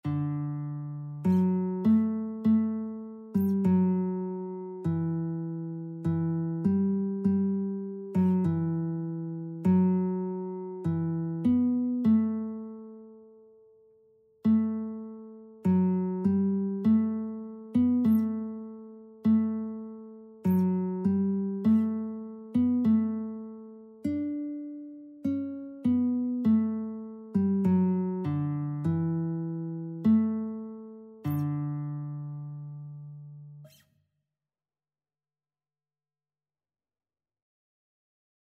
Christian Christian Lead Sheets Sheet Music My Faith Looks Up to Thee (Olivet)
D major (Sounding Pitch) (View more D major Music for Lead Sheets )
4/4 (View more 4/4 Music)
Classical (View more Classical Lead Sheets Music)